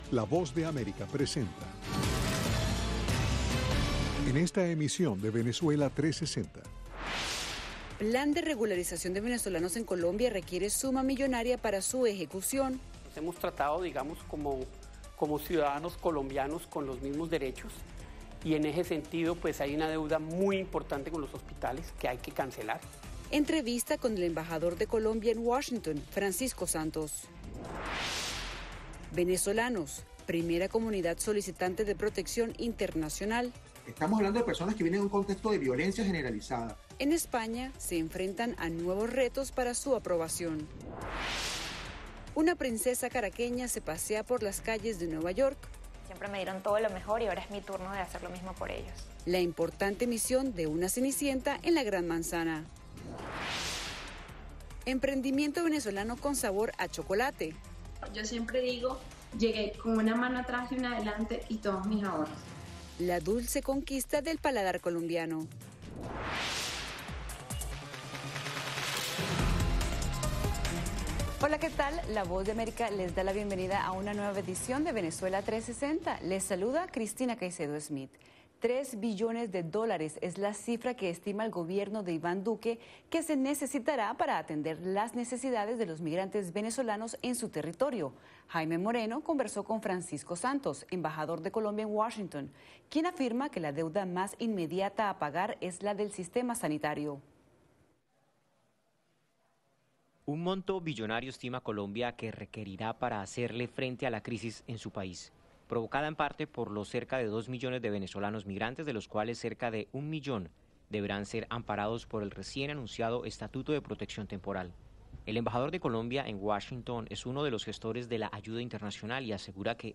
Unos tres mil millones de dólares es la cifra que estima el gobierno de Colombia que se necesitará para atender las necesidades de los migrantes venezolanos en su territorio. Venezuela 360 presenta entrevista con el embajador de Colombia en Washington.